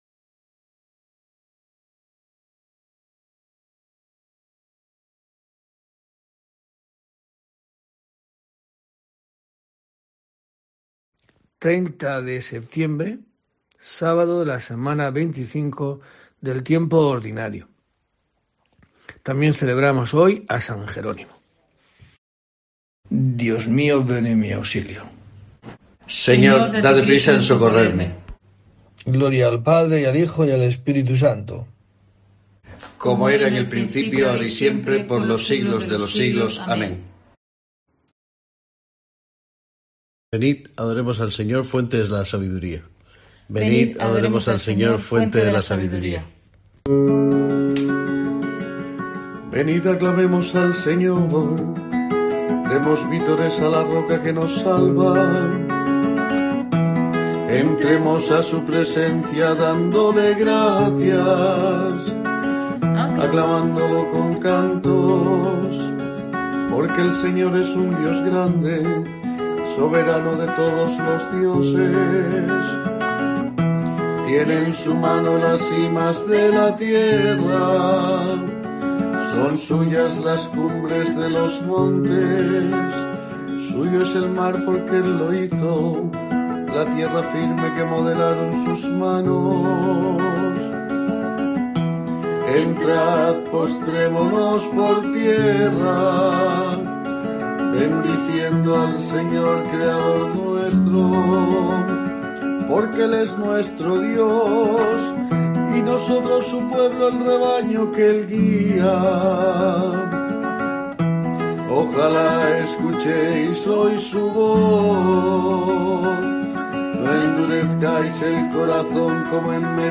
30 de septiembre: COPE te trae el rezo diario de los Laudes para acompañarte